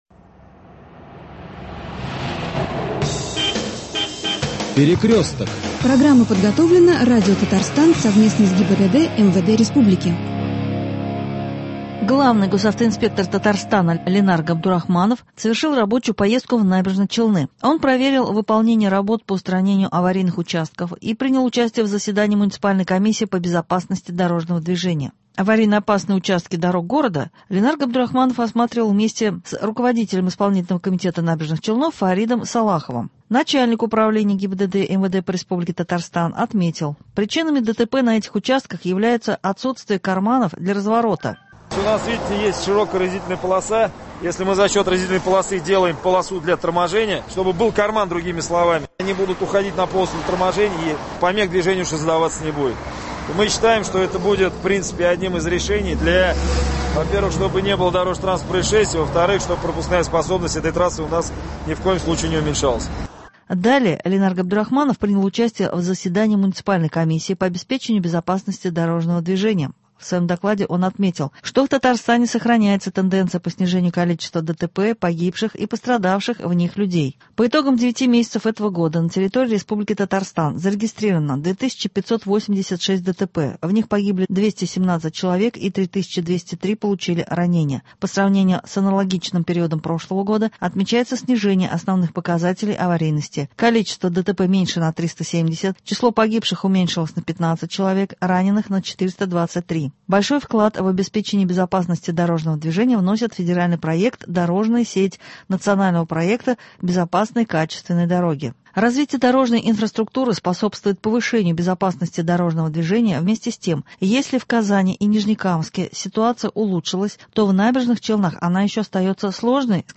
Новости ГИБДД.